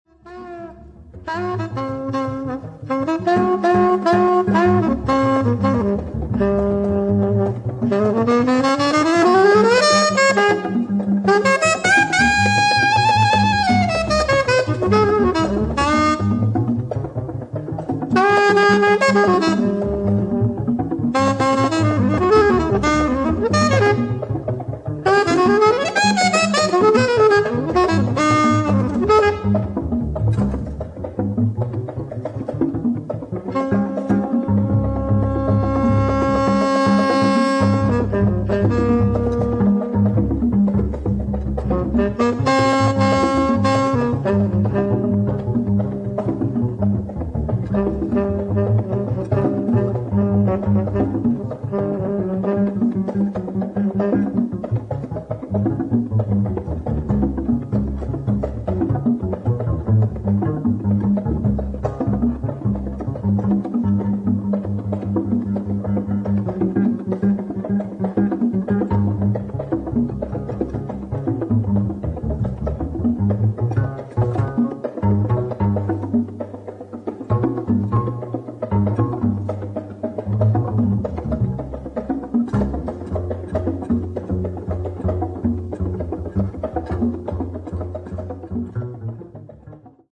シンプルながらも独特のムードと深みが感じられるアラブ音楽＋ジャズな素晴らしい作品